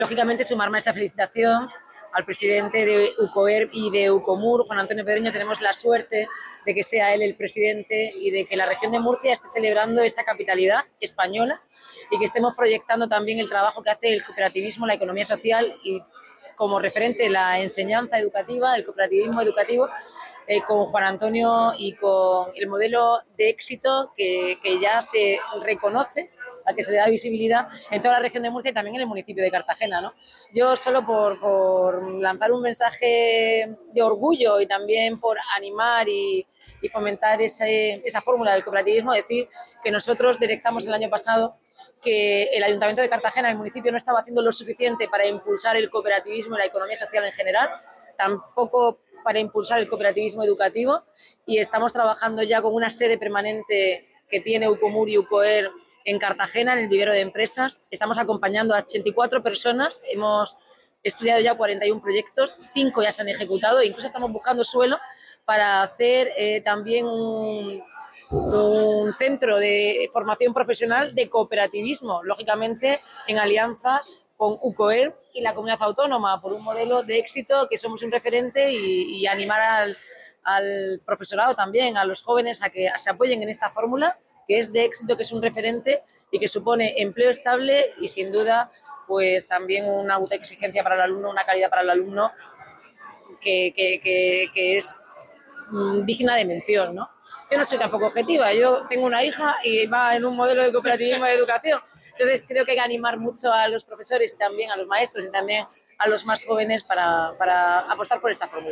Enlace a Declaraciones de la alcaldesa, Noelia Arroyo, en el XL Día de UcoeRM